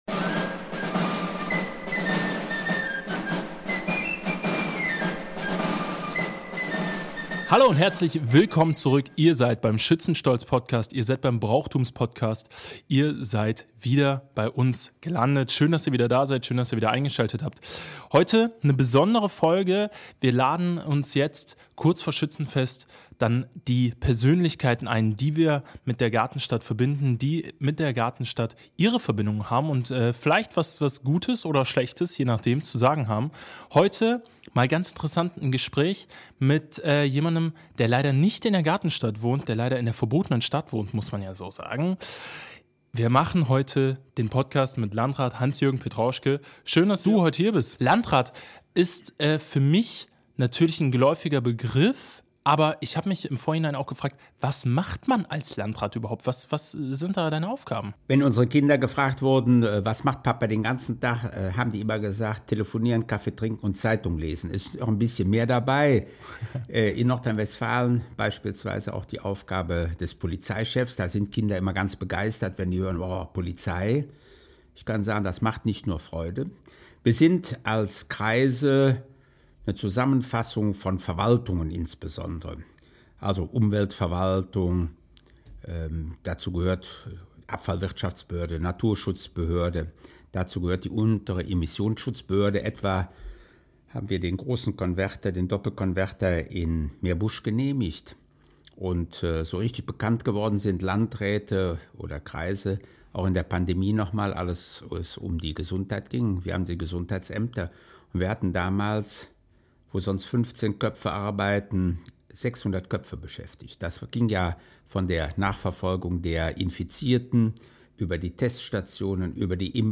In dieser Folge spricht er über die Herausforderungen im Rhein-Kreis Neuss, seine Erinnerungen an Wevelinghoven und warum Ehrenamt mehr ist als nur Tradition. Ein persönliches Gespräch über Politik, Brauchtum und die Frage, ob er selbst je die Königswürde angestrebt hat.